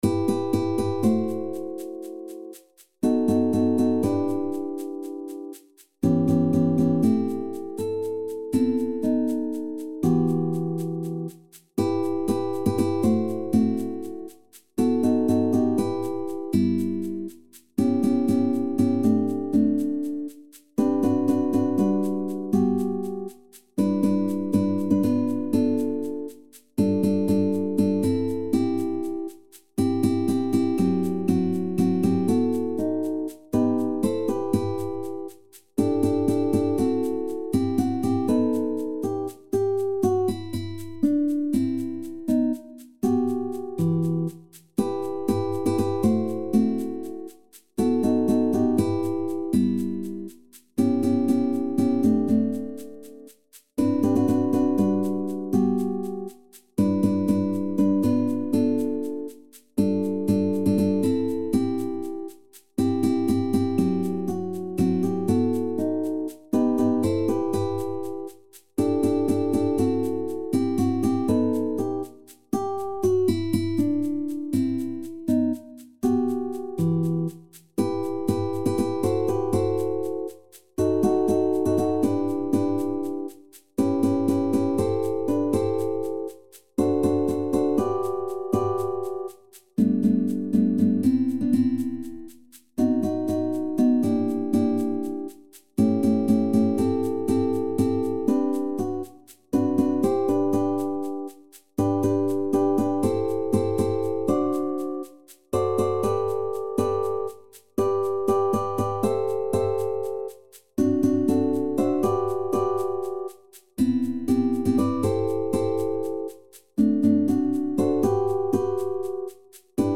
SSAA | SATB | SSATB | SSAB